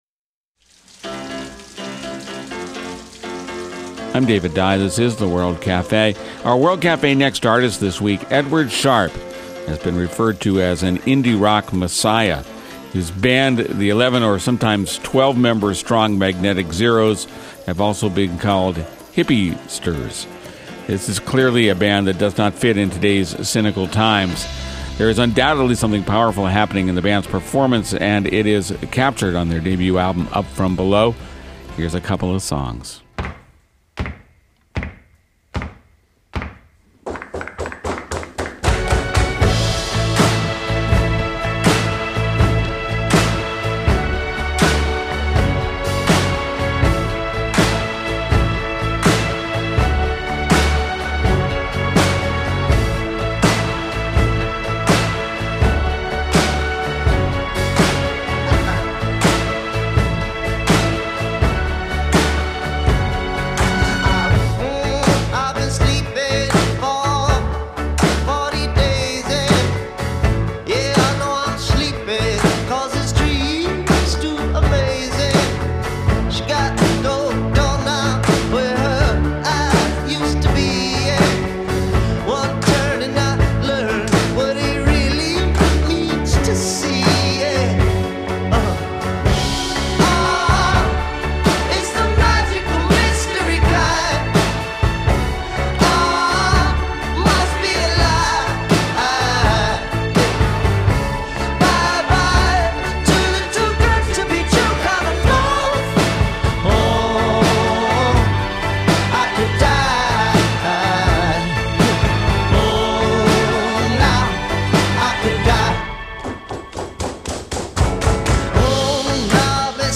Edward Sharpe and the Magnetic Zeros is a young 10-piece indie-folk group that has already earned a fevered following in its native Los Angeles, where its live shows have become legendary. With a percussive sound, an air of good-natured affection and lots of opportunities to clap and whistle along, it's one of America's most intriguing new bands.